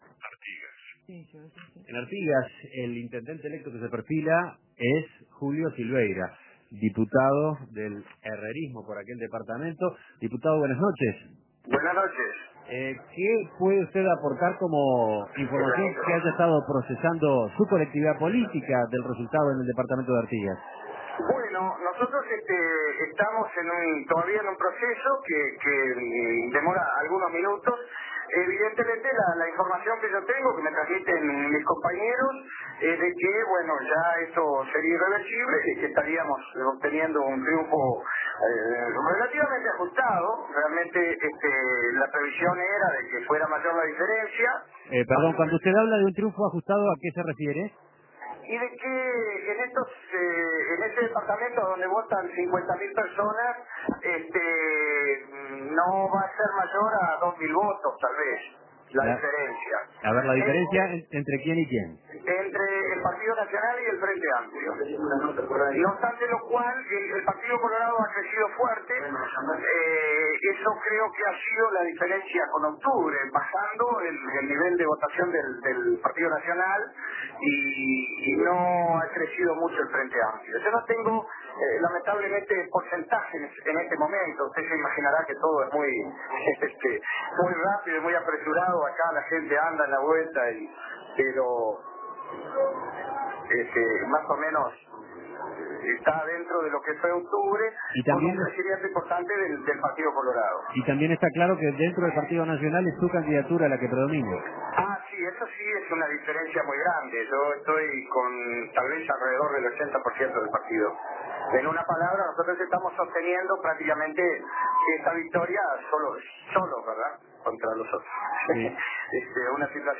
Entrevistas Artigas, otro de los bastiones colorados que pasó a manos blancas Imprimir A- A A+ El diputado Julio Silveira, del Partido Nacional triunfó en las elecciones del departamento de Artigas.